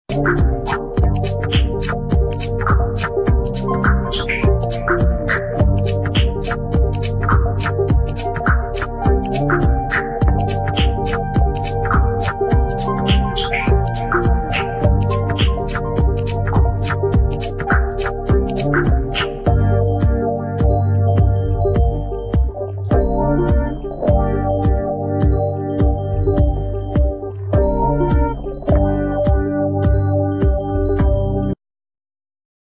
elektronische Musik